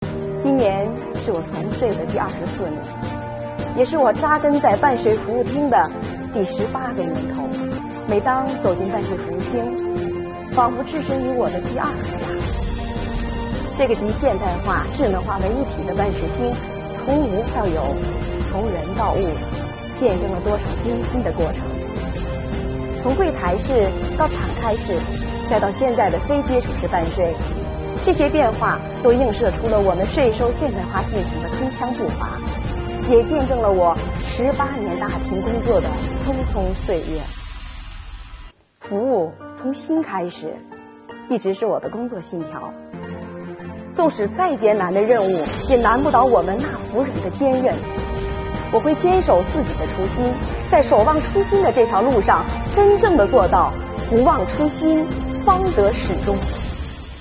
微宣讲共设100期，改变台上一人讲、台下大家听的方式，由身边人讲述自身感悟，引发大家共鸣。